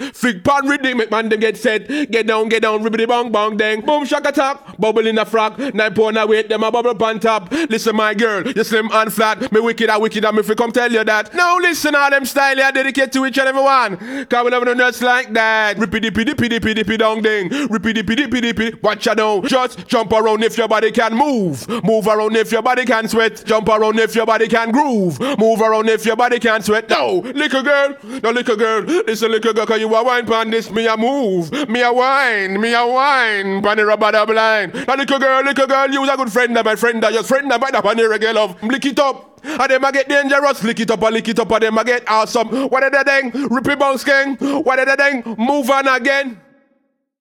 Ragga
Ragga jungle
cantar
Sonidos: Música
Sonidos: Voz humana